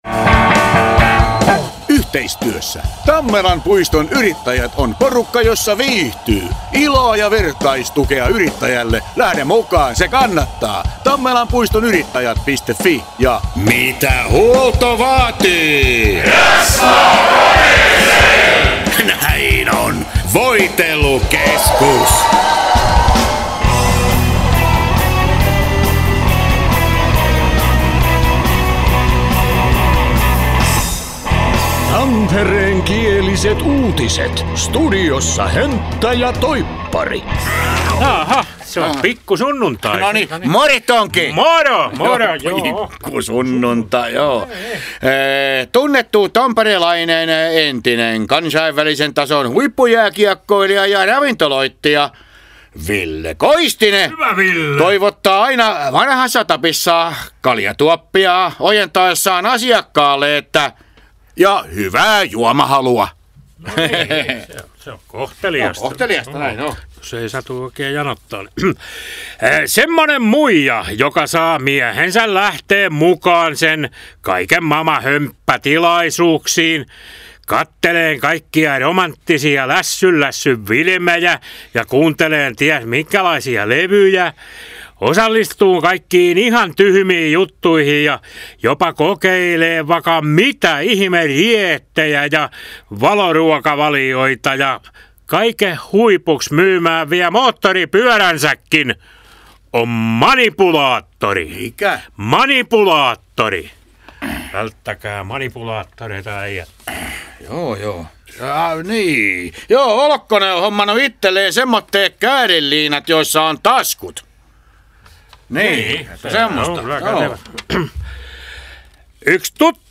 Tampereenkiäliset uutiset